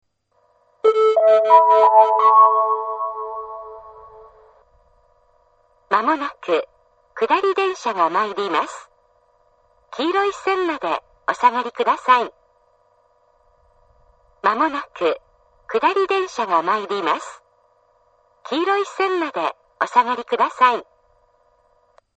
２番線接近放送